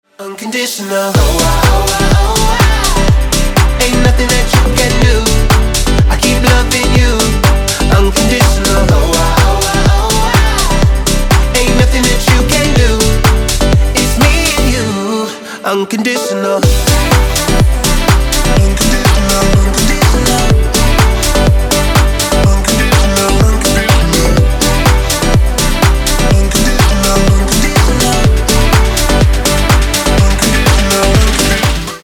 • Качество: 320, Stereo
красивый мужской голос
Electronic
пианино
энергичные
house